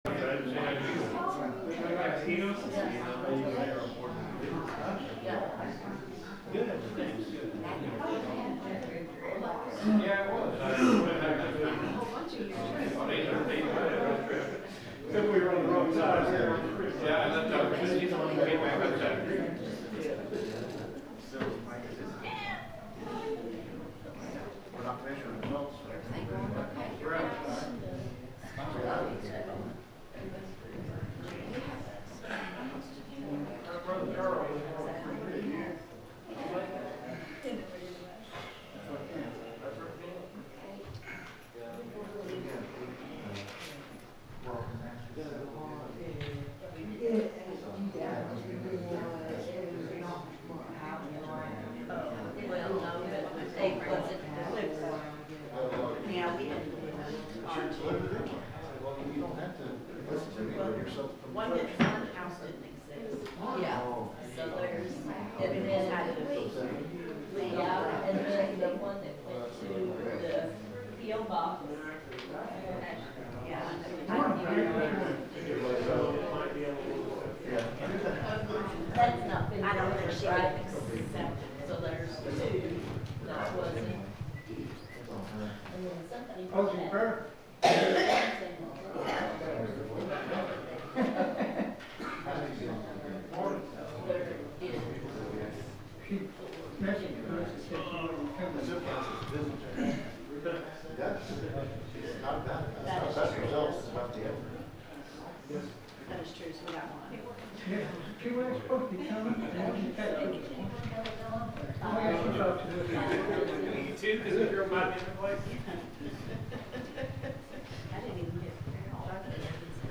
The sermon is from our live stream on 3/1/2026